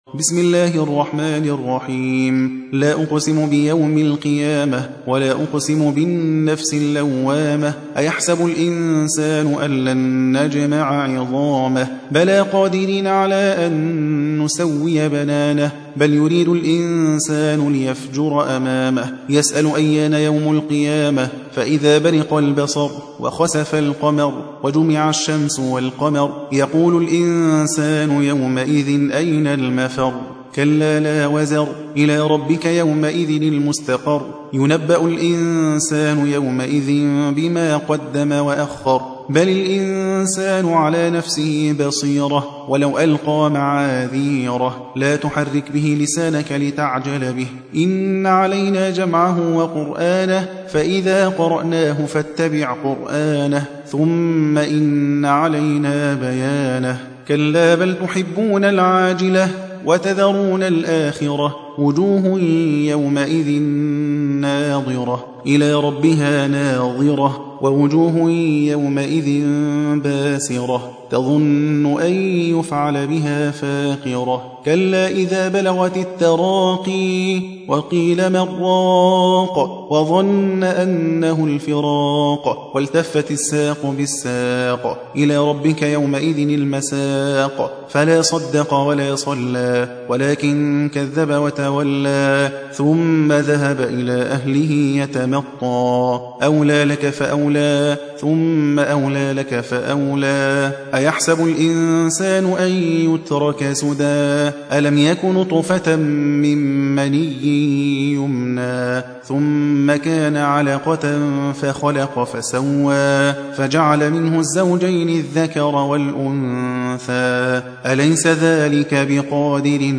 75. سورة القيامة / القارئ